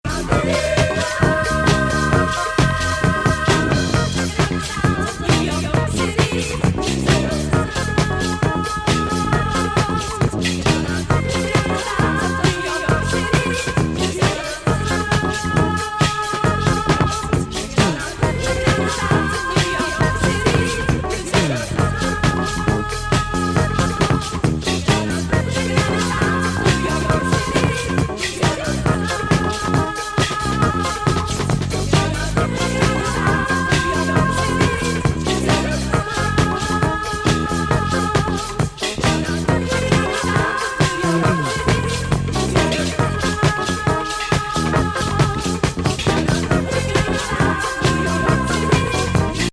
パーカッションがGOOD◎